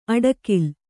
♪ aḍakil